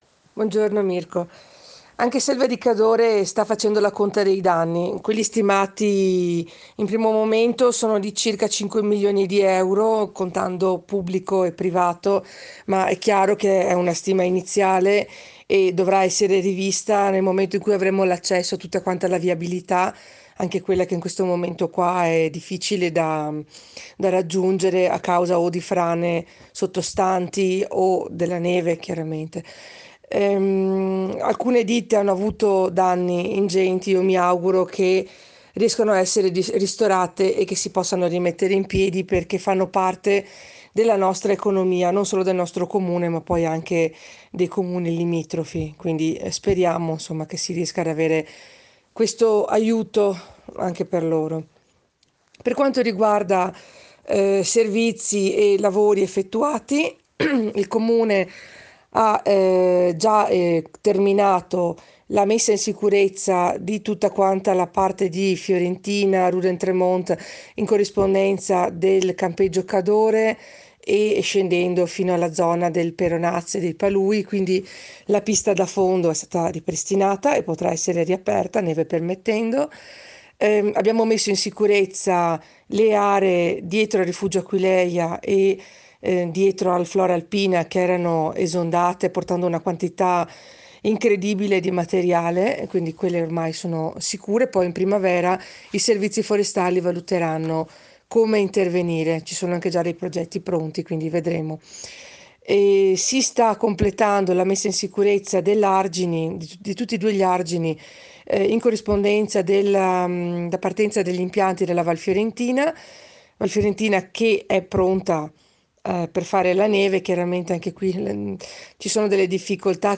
INTERVENTO DEL SINDACO, SILVIA CESTARO AL GIORNALE RADIO DI RADIO PIU’.